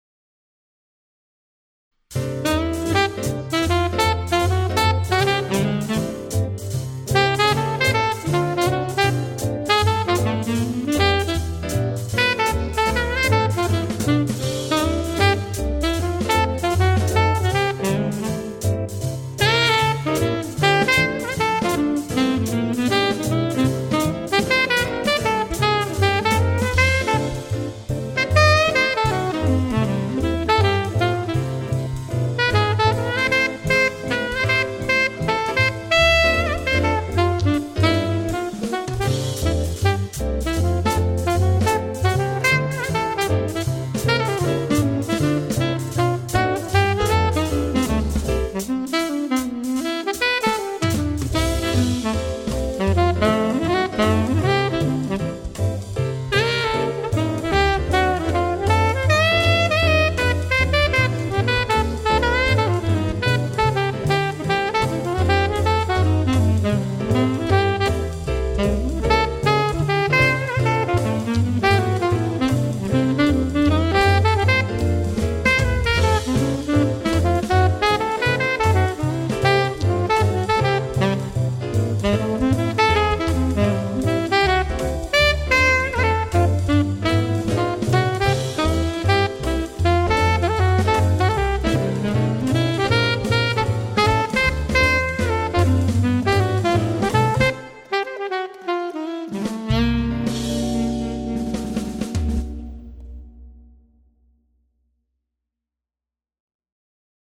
12 Jazz Etudes Based on Popular Standards
Jazz Improvisation